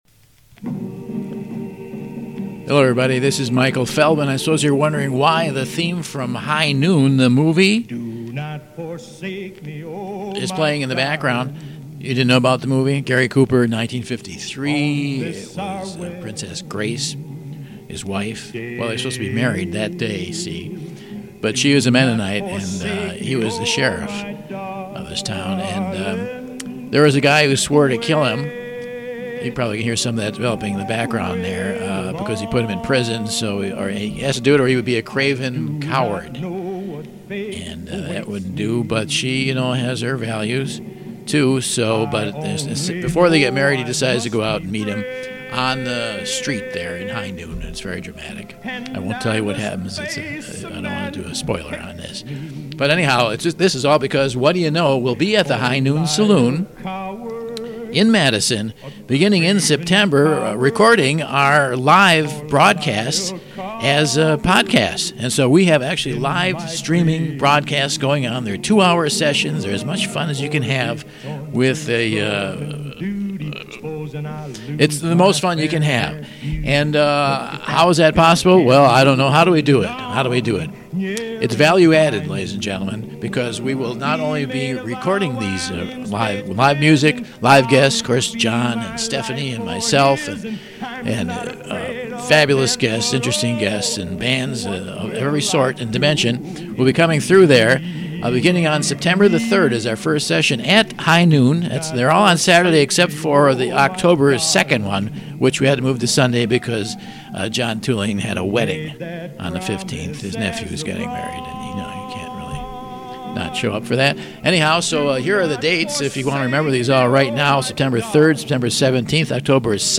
And here’s the promo just to make it official!
high-noon-promo.mp3